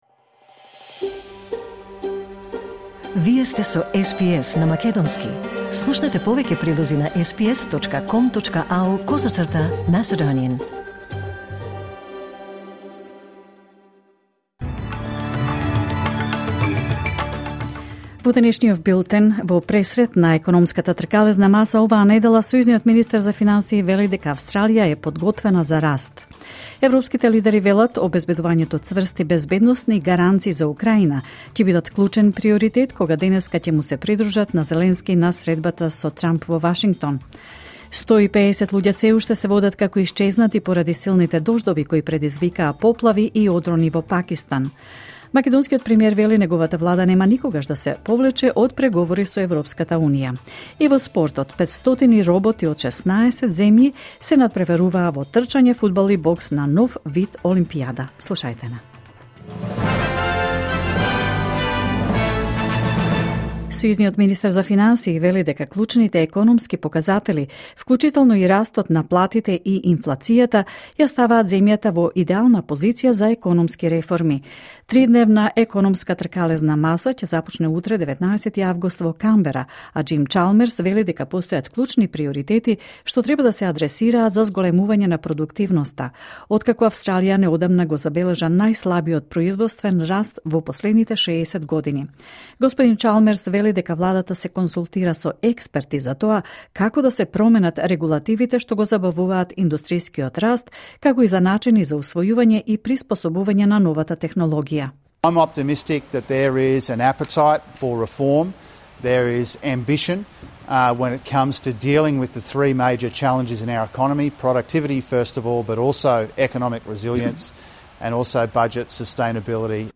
Вести на СБС на македонски 18 август 2025